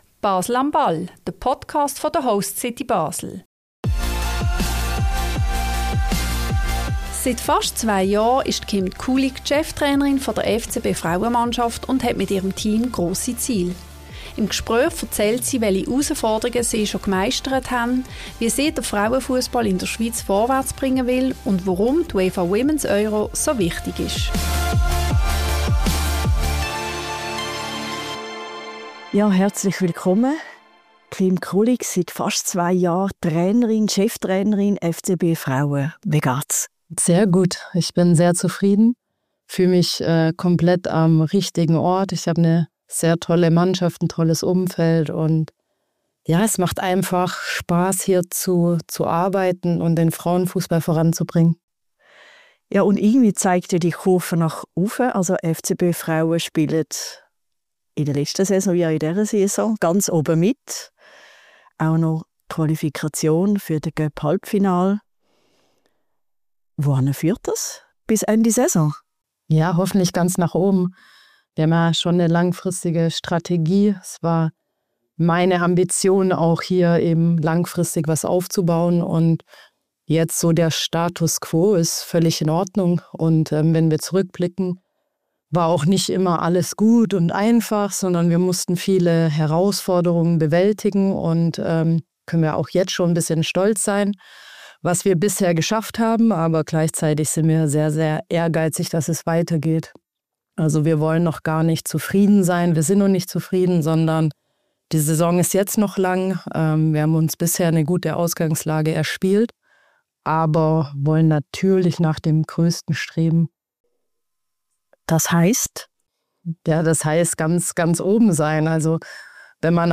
Seit fast zwei Jahren ist Kim Kulig Cheftrainerin der FCB-Frauenmannschaft und hat mit ihrem Team grosse Ziele. Im Gespräch erzählt sie, welche Herausforderungen bereits gemeistert haben, wie sie den Frauenfussball in der Schweiz weiterbringen möchte und warum die UEFA Women's Euro so wichtig ist.